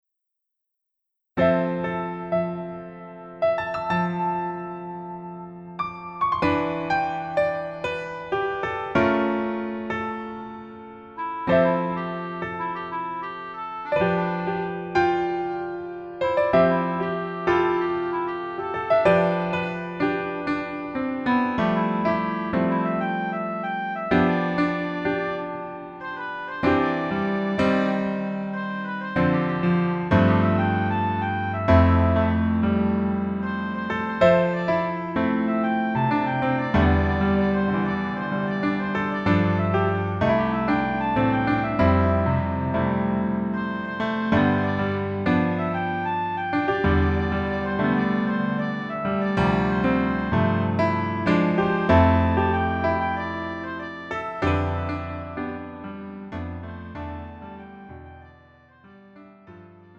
음정 남자키 3:26
장르 가요 구분 Pro MR